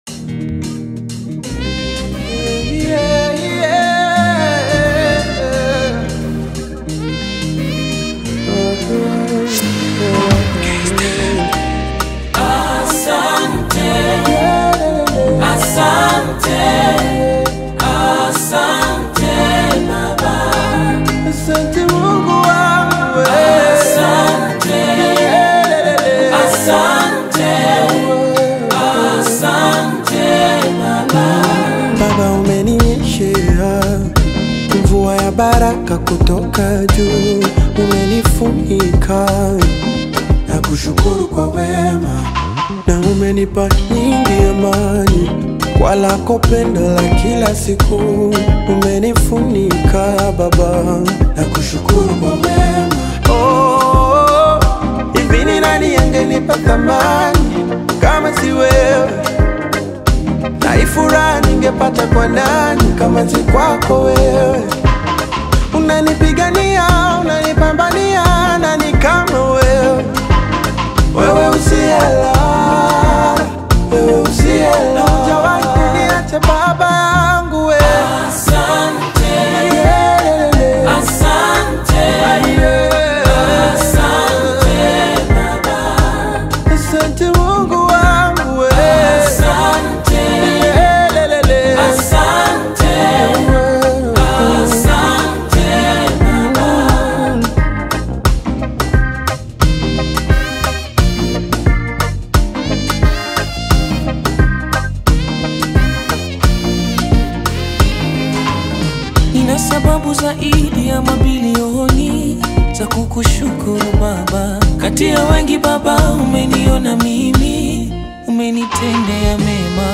Renowned gospel artist
Through soulful vocals and meaningful lyrics